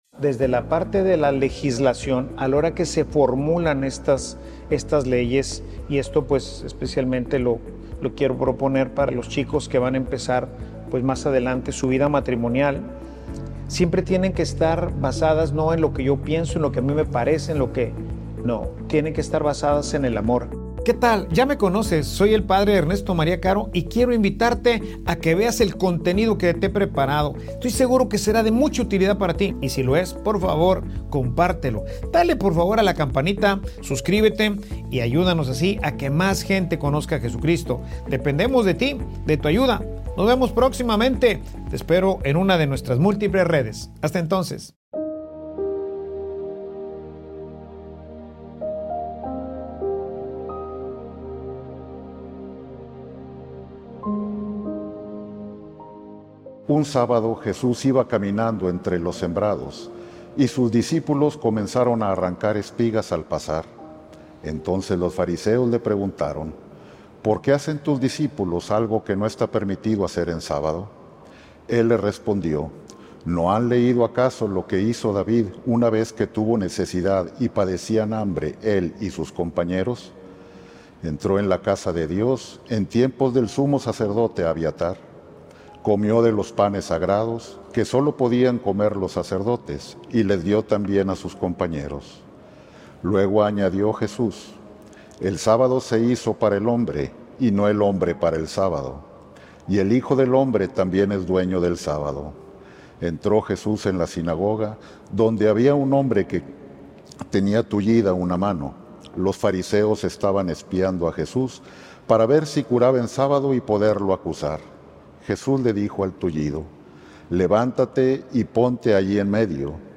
Homilia_Ninguna _excusa_vale _para_no_amar.mp3